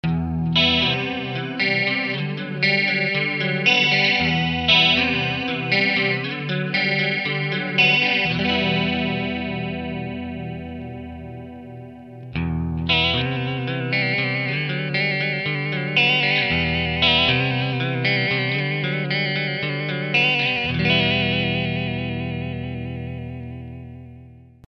Modifying a Boss CE-2 Chorus by adding a Dizzying, Spinning, Underwater "Vibe" switch.
Boss Vibro-Chorus Mod
stock Chorus and a very cool True Vibrato effect.
VibroChorus2.mp3